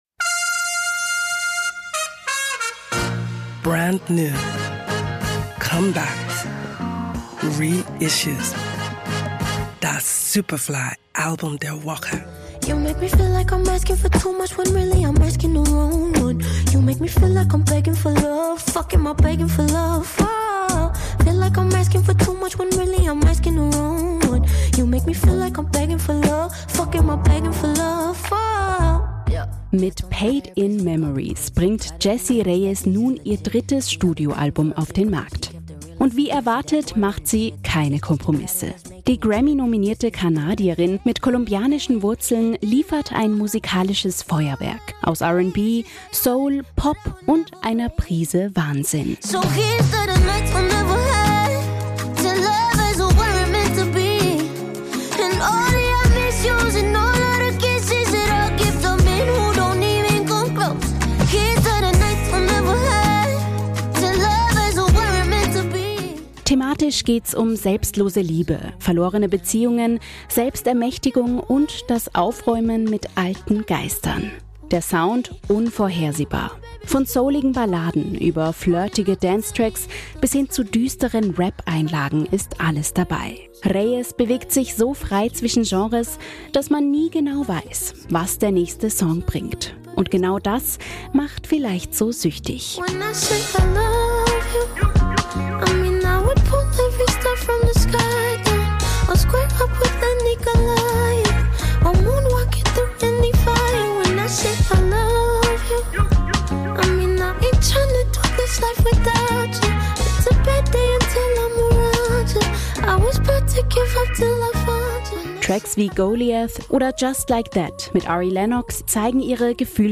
Die Grammy-nominierte Kanadierin mit kolumbianischen Wurzeln liefert ein musikalisches Feuerwerk aus R&B, Soul, Pop und einer Prise Wahnsinn.